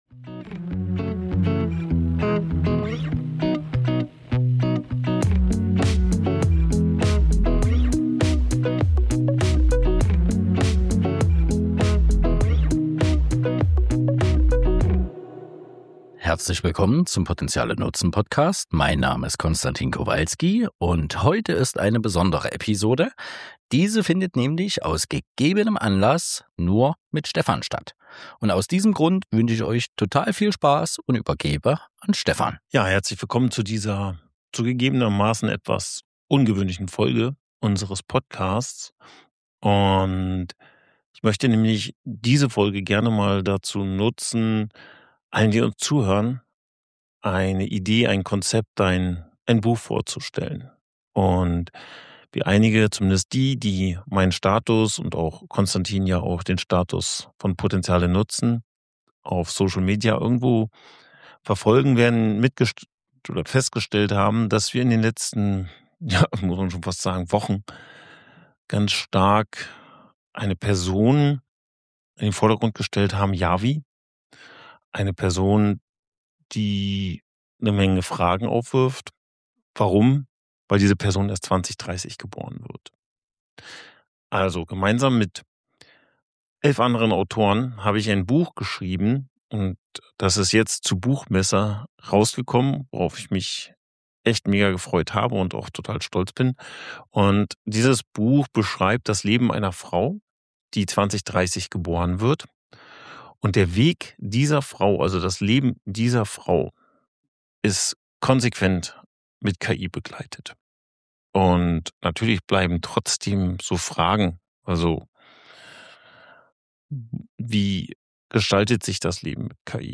In dieser besonderen Solo-Folge